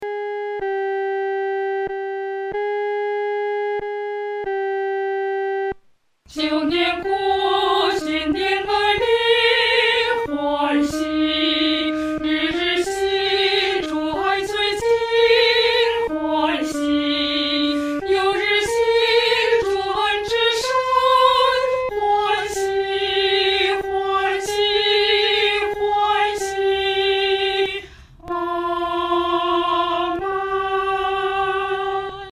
女低
本首圣诗由网上圣诗班录制